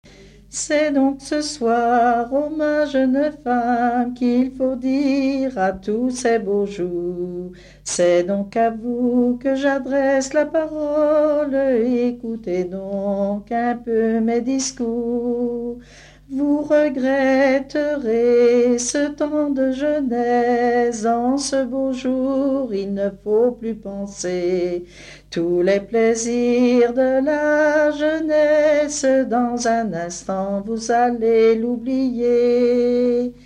chanson de noce
Pièce musicale inédite